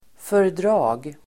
Uttal: [för_dr'a:g]